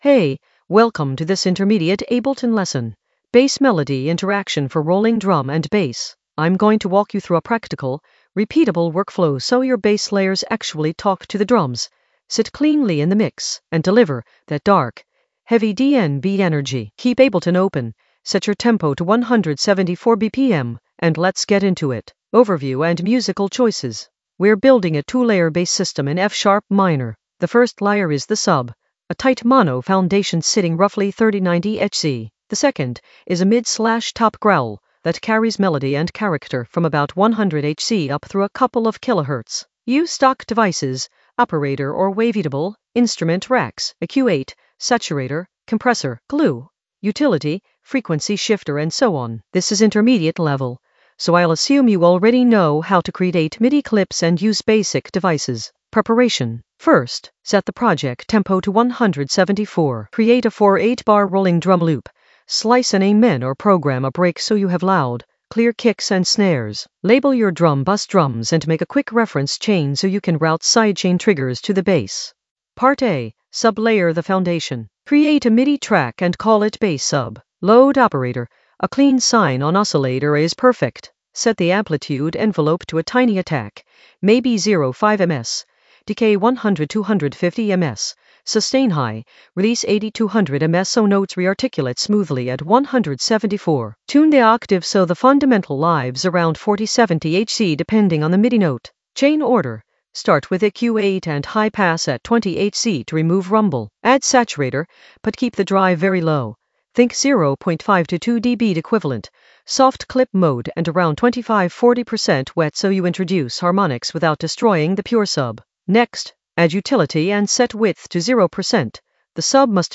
An AI-generated intermediate Ableton lesson focused on Bass melody interaction in the Composition area of drum and bass production.
Narrated lesson audio
The voice track includes the tutorial plus extra teacher commentary.